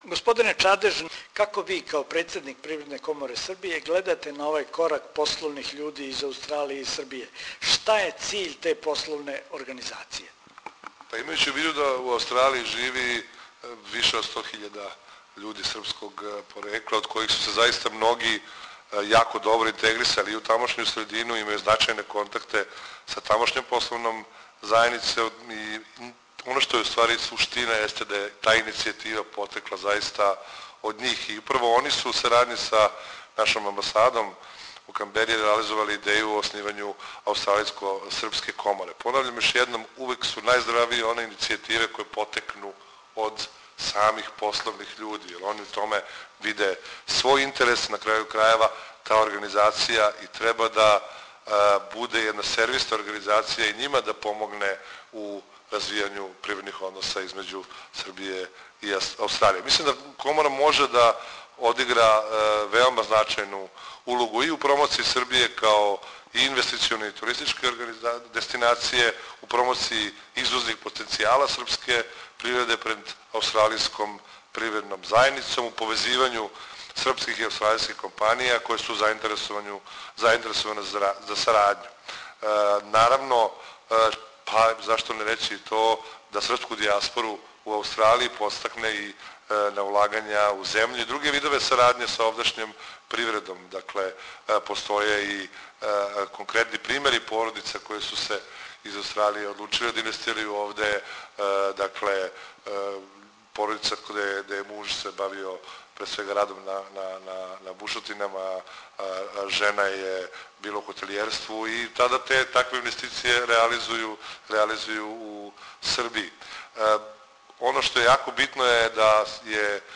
Међутим шта Аустралијко-спрка привредна комора значи за привреду Србије и тамошње пословне људе можете чути у разговору са Марком Чадежом, председником Привредне коморе Србије.